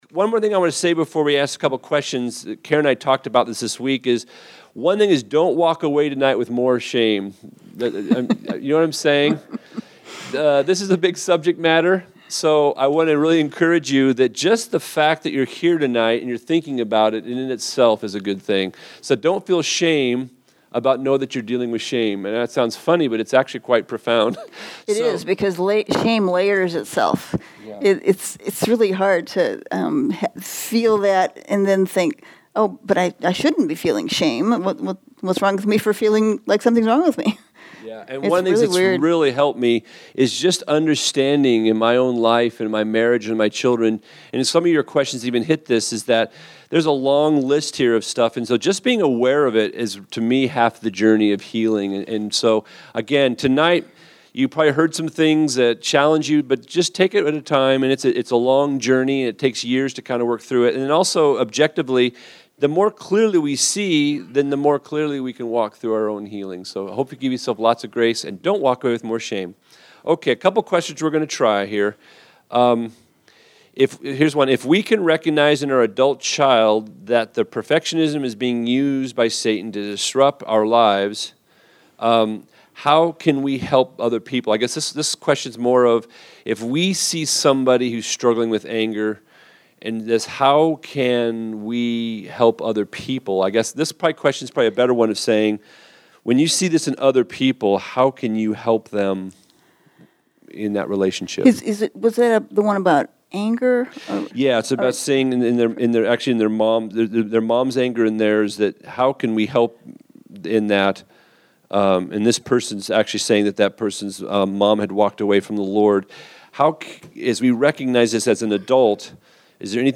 This is the question and answer portion of “Defining Shame,” which explores how shame defines us until we get self awareness and healing. This Q & A portion is in a discussion format.